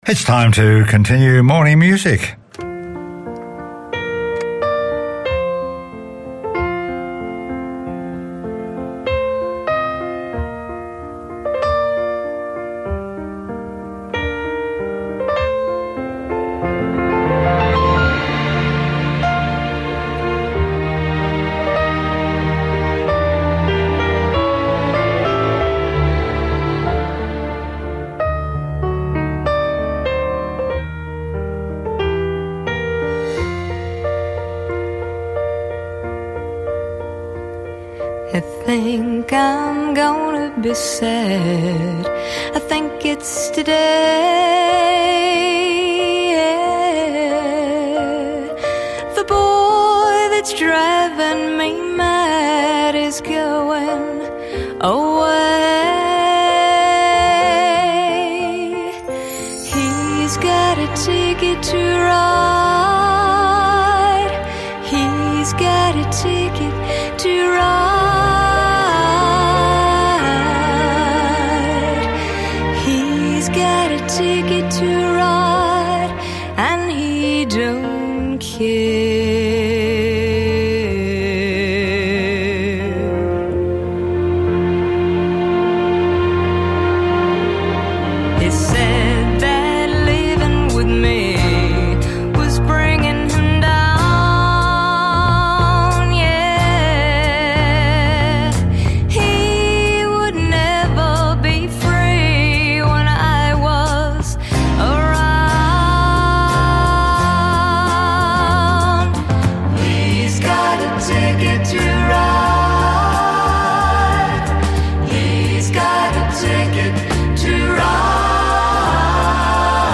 popular music from pre rock & roll eras and beyond